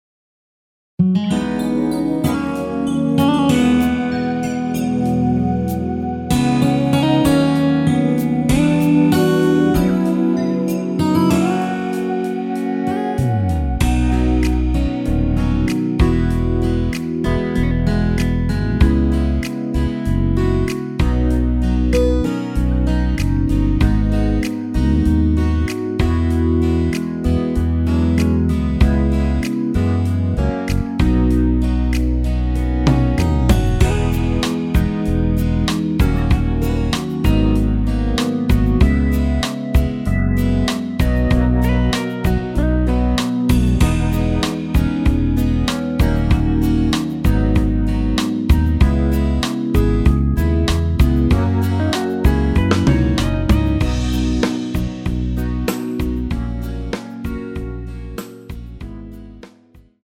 앞부분30초, 뒷부분30초씩 편집해서 올려 드리고 있습니다.
중간에 음이 끈어지고 다시 나오는 이유는
곡명 옆 (-1)은 반음 내림, (+1)은 반음 올림 입니다.